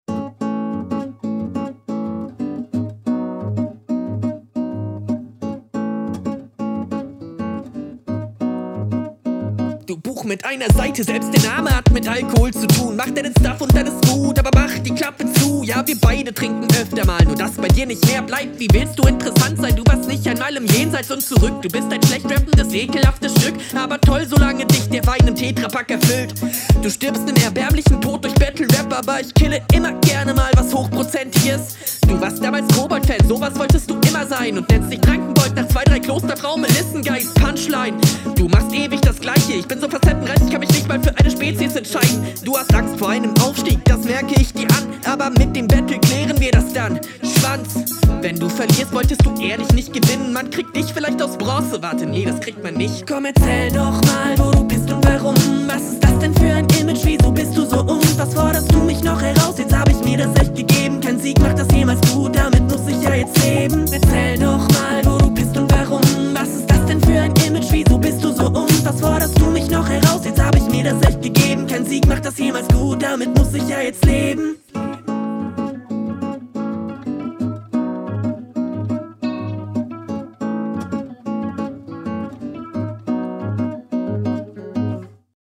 Mag die Hook.